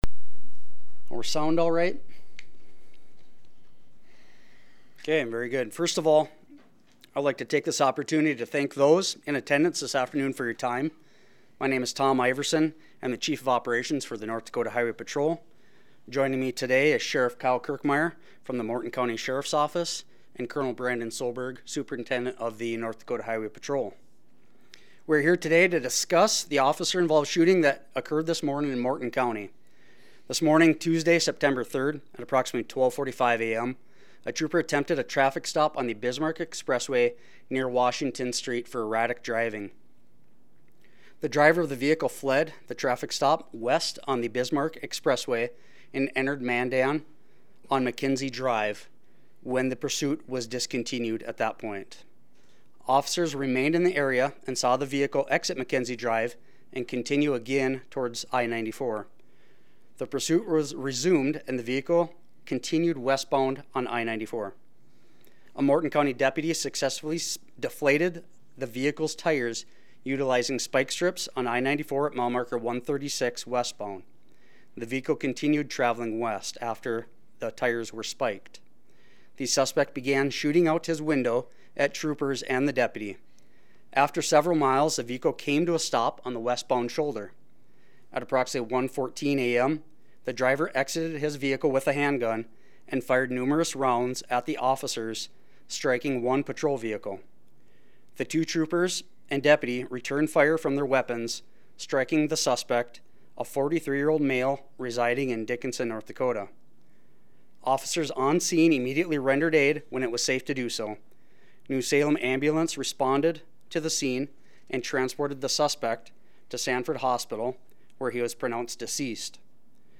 Press Conference Audio Recording